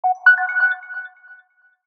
Turquoise Alert.ogg